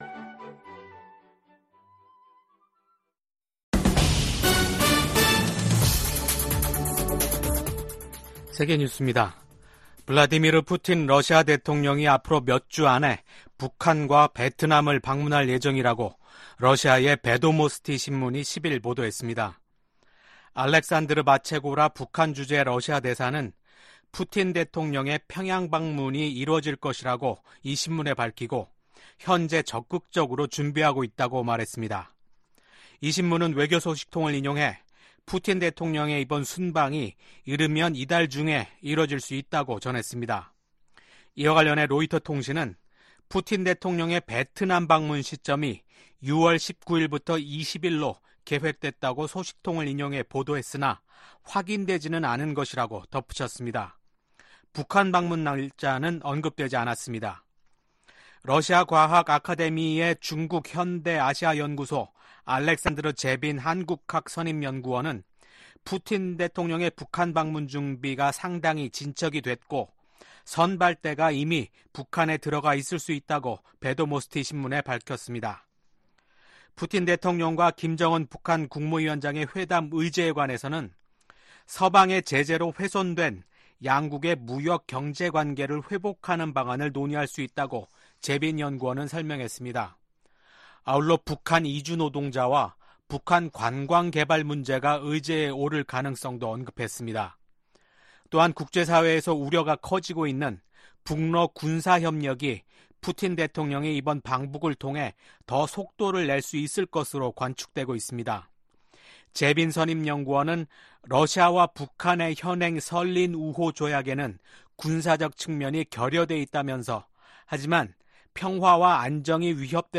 VOA 한국어 아침 뉴스 프로그램 '워싱턴 뉴스 광장' 2024년 6월 11일 방송입니다. 김여정 북한 노동당 부부장은 한국이 전단 살포와 확성기 방송을 병행하면 새로운 대응에 나서겠다고 위협했습니다. 한국 정부는 한국사회에 혼란을 야기하는 북한의 어떤 시도도 용납할 수 없다고 경고했습니다. 미국의 백악관 국가안보보좌관이 북한, 중국, 러시아 간 핵 협력 상황을 면밀이 주시하고 있다고 밝혔습니다.